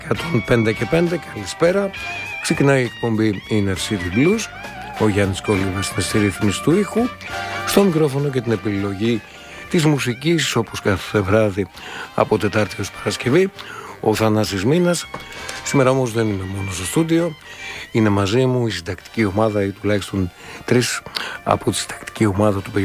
εκπομπή